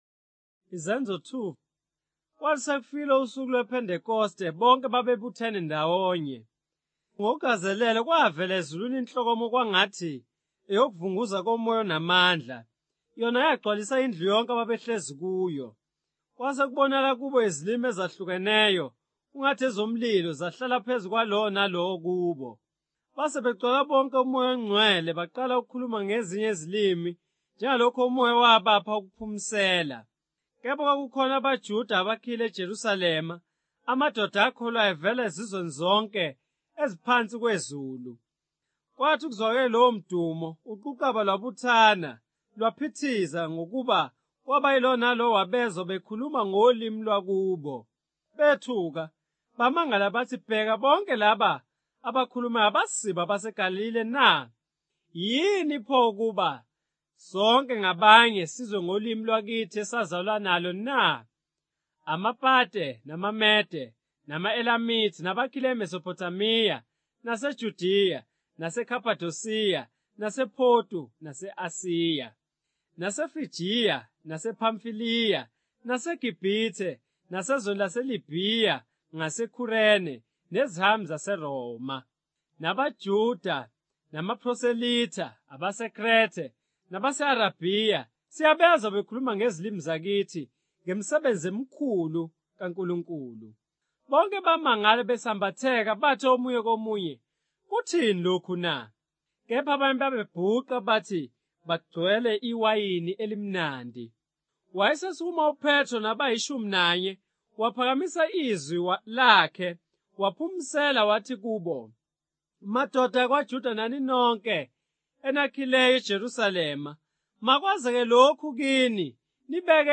Isahluko yeBhayibheli Zulu, ne ukulandisa okulalelwayo